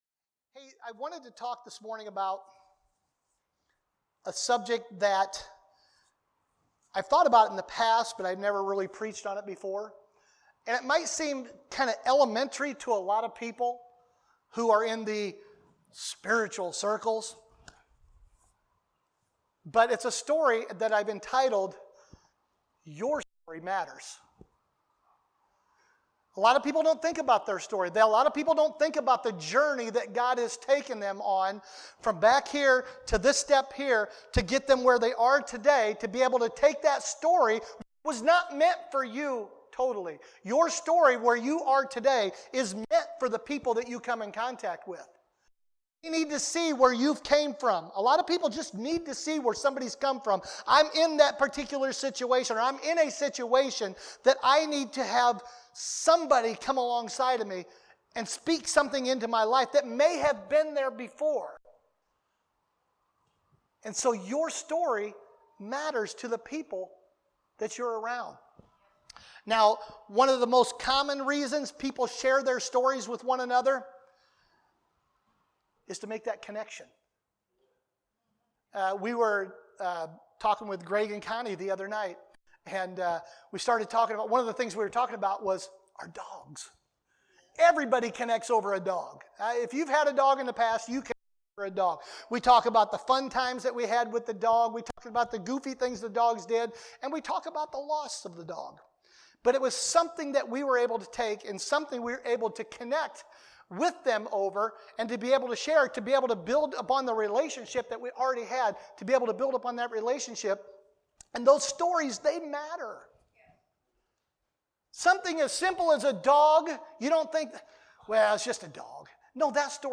Sermons | LifePointe Church